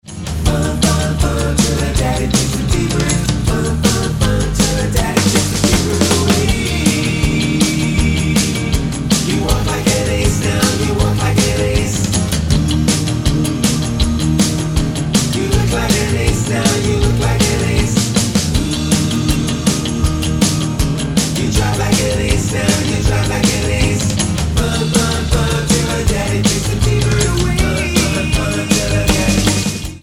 Tonart:Eb mit Chor